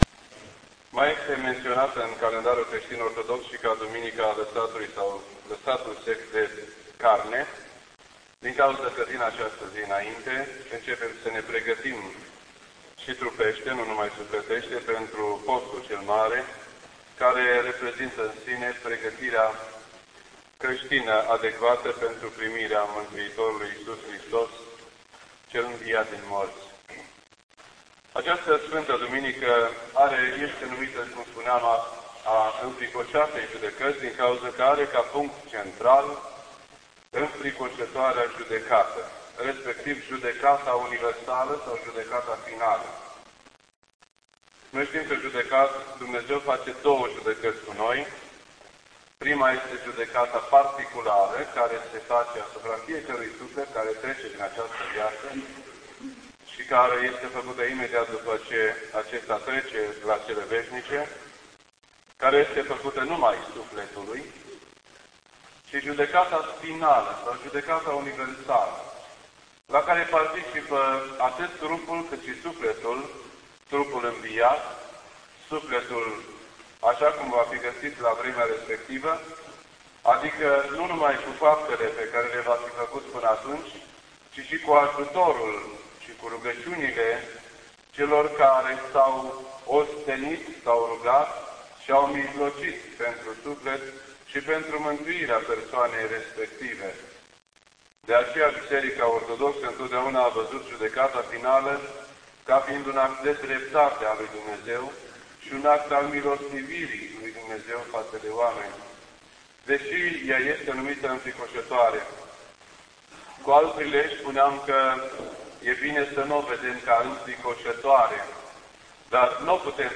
This entry was posted on Sunday, February 11th, 2007 at 10:22 AM and is filed under Predici ortodoxe in format audio.